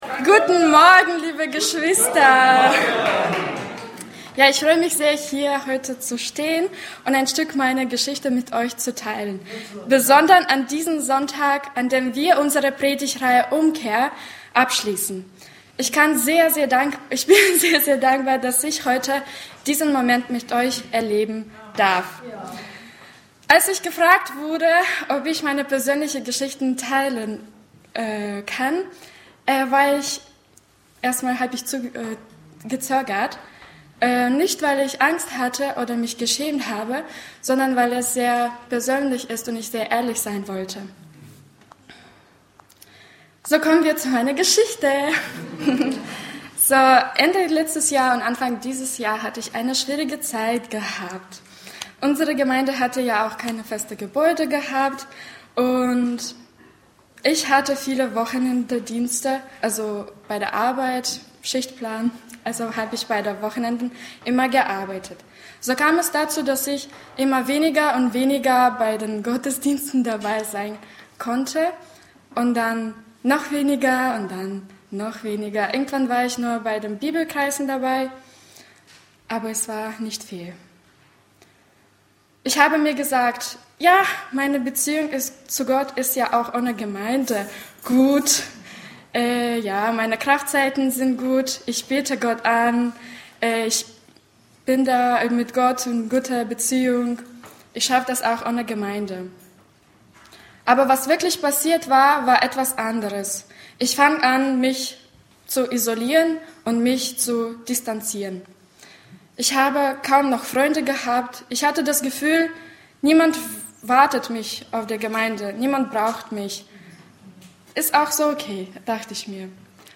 E-Mail Details Predigtserie: Abendmahl Datum